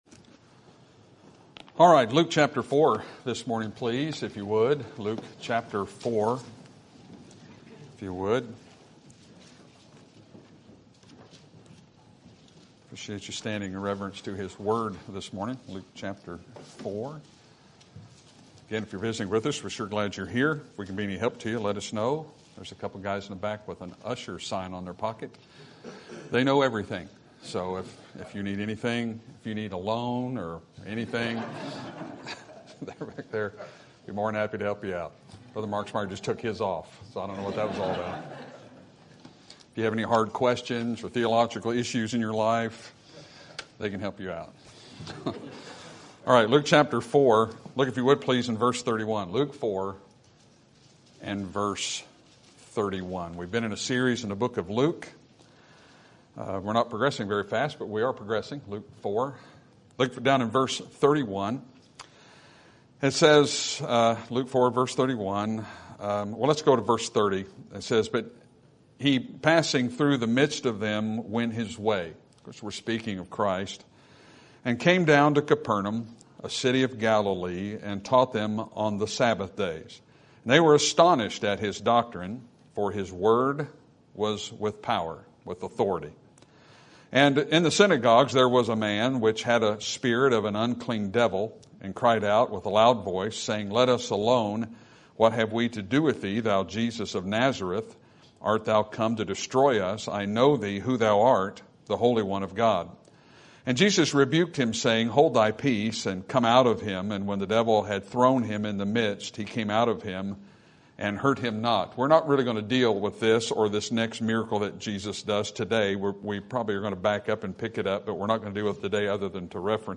Sermon Topic: Book of Luke Sermon Type: Series Sermon Audio: Sermon download: Download (27.46 MB) Sermon Tags: Luke Gospel Salvation Jesus